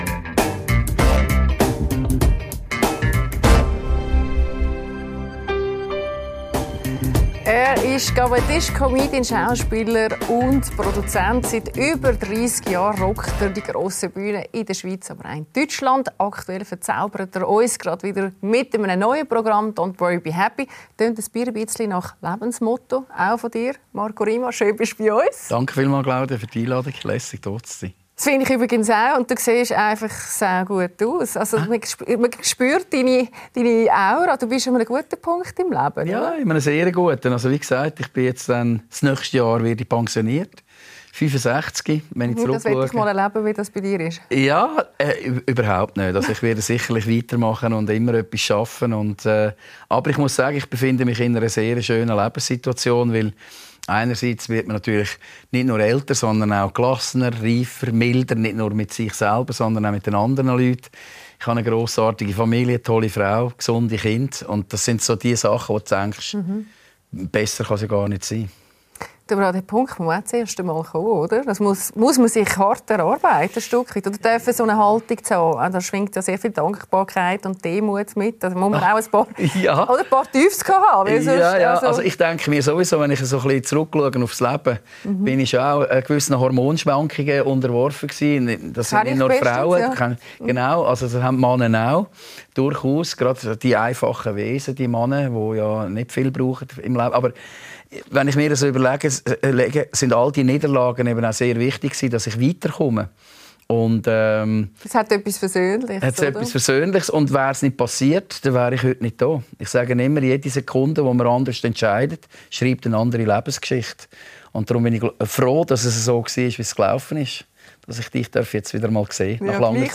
Kabarettist Marco Rima spricht bei Claudia Lässer über sein Programm «Don’t Worry, Be Happy», die Folgen der Kontroversen rund um seine Corona-Kritik – und warum er dem Humor trotzdem treu bleibt.